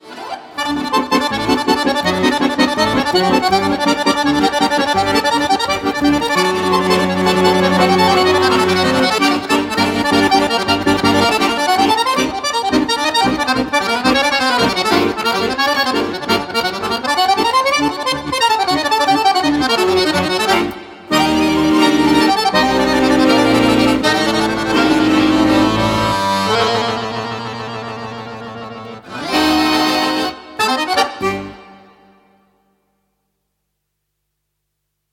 freely improvising variations for each song